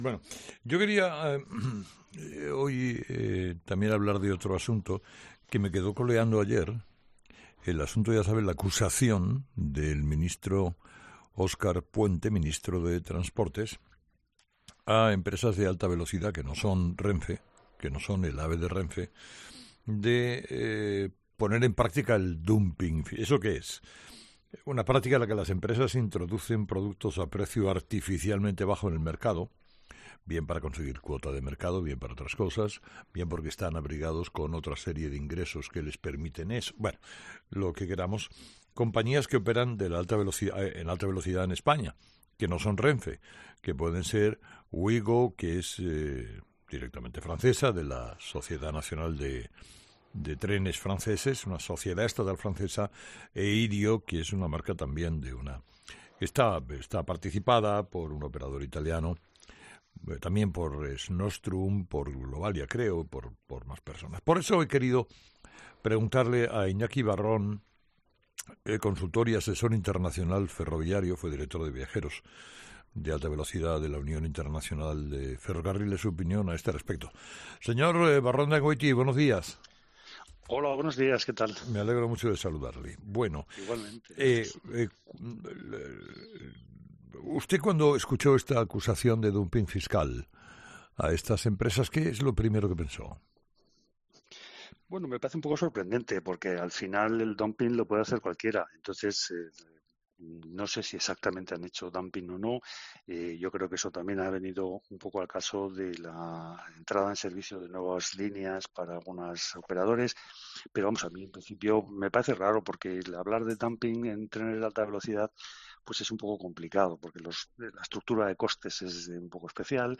consultor y asesor ferroviario